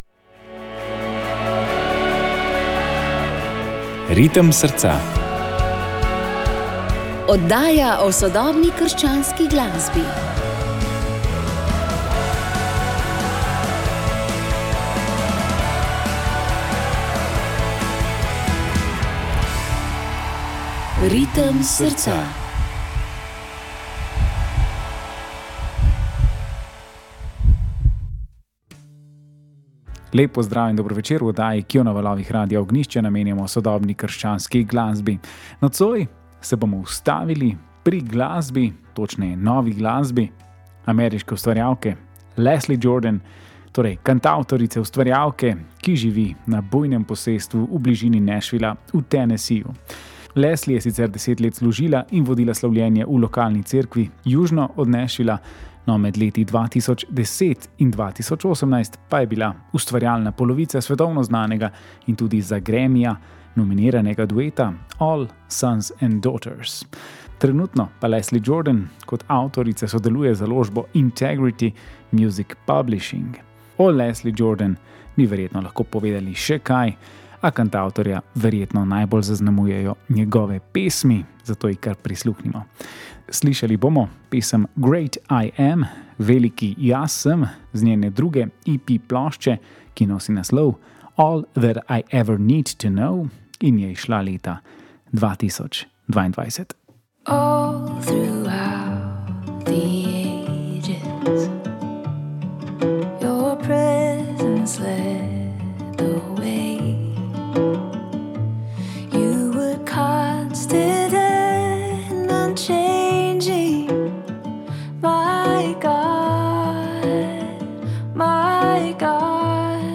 Tokrat smo objavili drugi del pogovora s kanadskim kantavtorjem Matt Maherom, ki smo ga v začetku junija poseli v hrvaškem Pulju. Slišali smo nekaj njegovih misli o bogastvu katoliške liturgije, cerkvenih delih leta ter o izbiri in umeščanju pesmi, ki naj služijo nekemu točno določenemu trenutku in namenu. Povedal pa je tudi zakaj se mu zdita sprava v Cerkvi ter ekumenizem eni bolj važnih nalog in priložnosti, ki čakata vesoljno Cerkev in kristjane različnih denominacij.